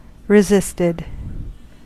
Ääntäminen
Ääntäminen US Haettu sana löytyi näillä lähdekielillä: englanti Resisted on sanan resist partisiipin perfekti.